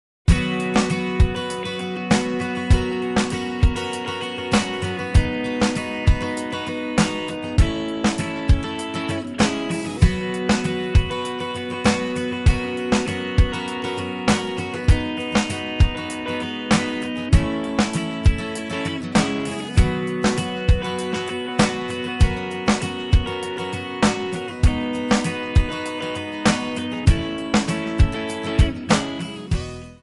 Backing track files: Rock (2136)
Buy With Backing Vocals.